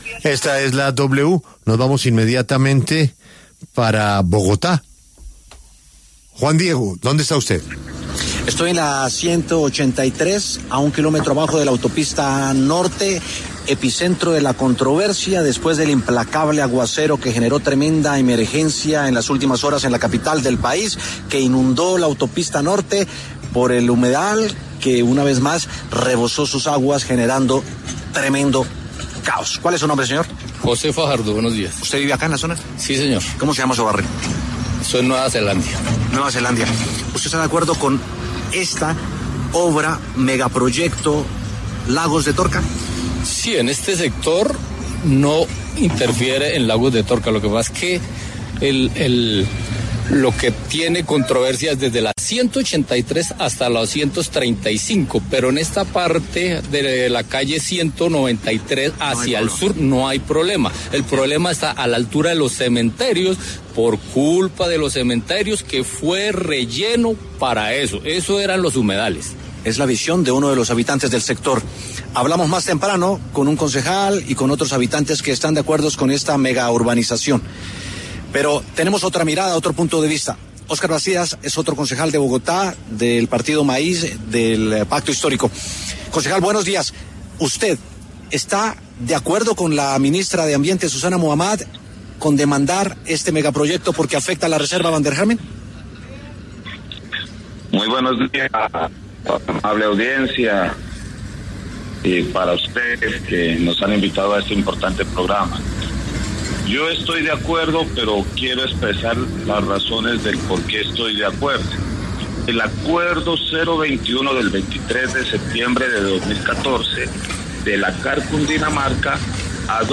Debate: ¿Cómo resolver el proyecto de la av Boyacá sin afectar la reserva Van der Hammen?
El concejal de Bogotá Óscar Bastidas conversó con La W a propósito del proyecto de la ampliación de la avenida Boyacá y que afectaría la reserva Van der Hammen en el norte de Bogotá.